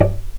vc_pz-D5-pp.AIF